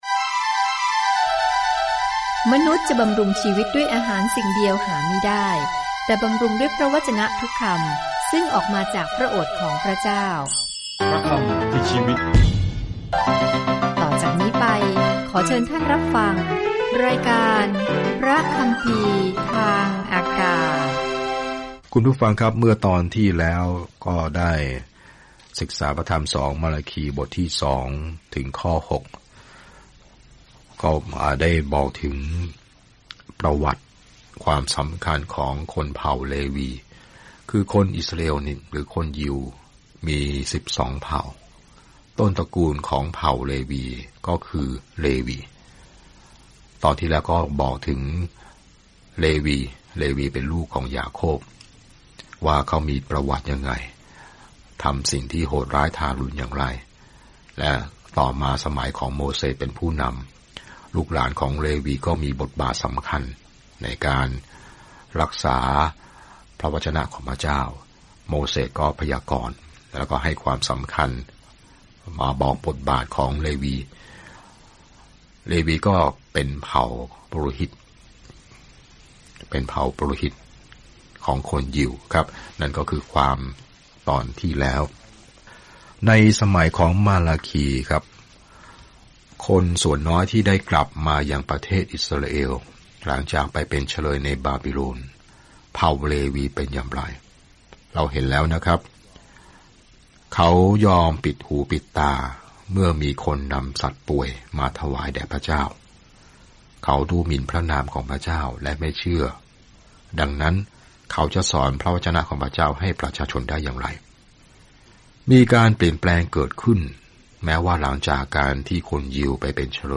มาลาคีเตือนชาวอิสราเอลที่ขาดการเชื่อมต่อว่าเขาได้รับข้อความจากพระเจ้าก่อนที่พวกเขาจะอดทนต่อความเงียบอันยาวนาน ซึ่งจะจบลงเมื่อพระเยซูคริสต์เสด็จเข้าสู่เวที เดินทางทุกวันผ่านมาลาคีในขณะที่คุณฟังการศึกษาด้วยเสียงและอ่านข้อที่เลือกจากพระวจนะของพระเจ้า